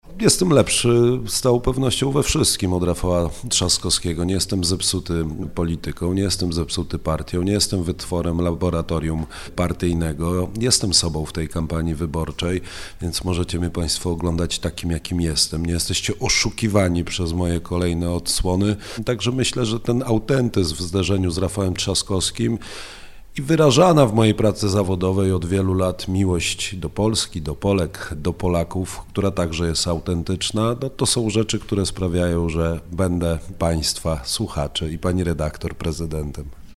Karol Nawrocki – kandydat na urząd prezydenta popierany przez PiS był gościem Radia Rodzina. Rozmawialiśmy o patriotyzmie, kierunku polskiej edukacji, redukcji liczby godzin religii, światopoglądzie.